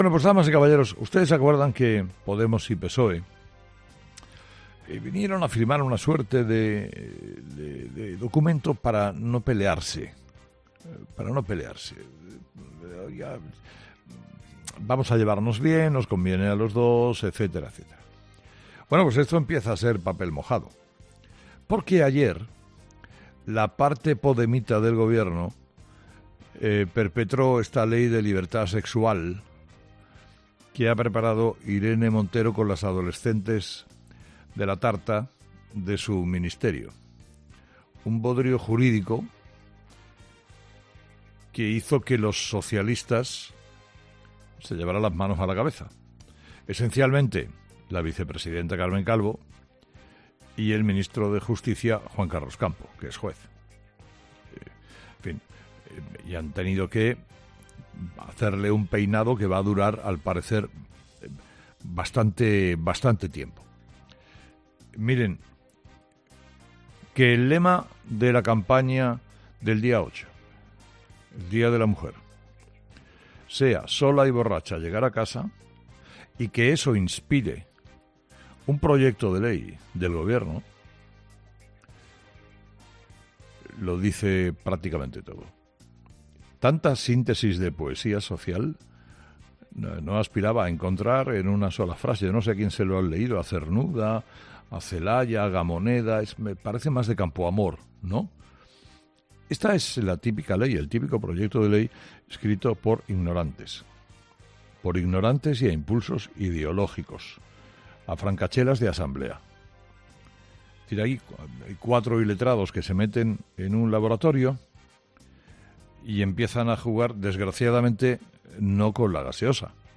Carlos Herrera ha comentado en su monólogo de las 06.00 las tensiones que la nueva Ley de Libertad Sexual, la llamada ley del 'sí es sí', está provocando en el seno del Gobierno de coalición.